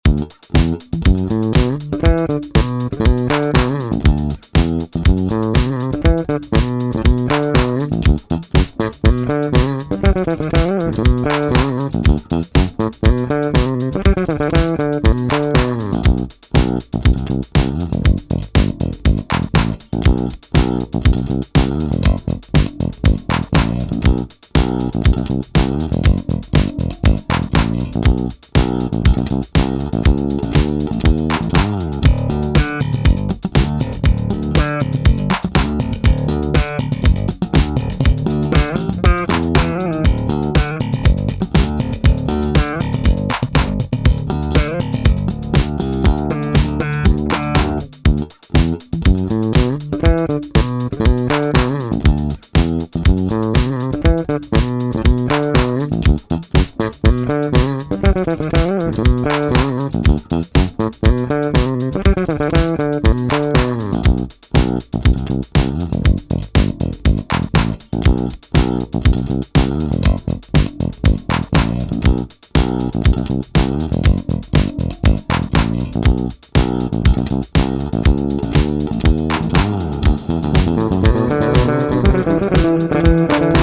ein epi thunderbird IV